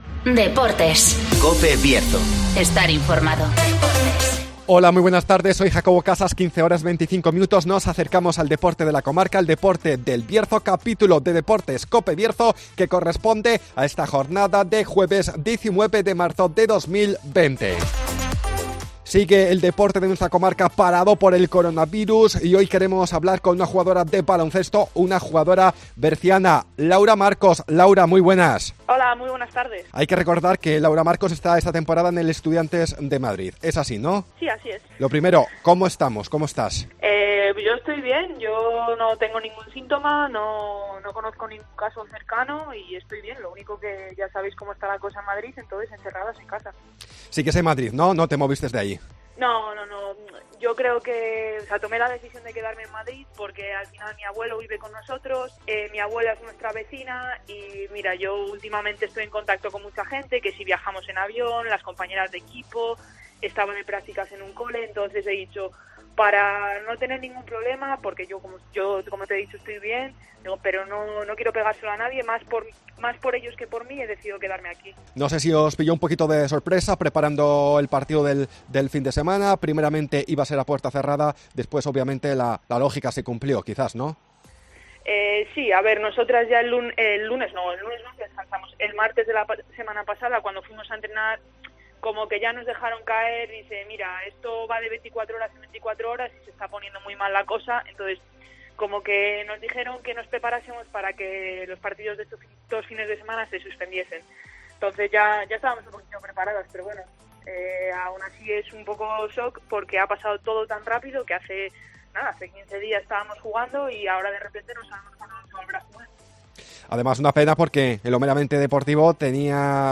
-Entrevista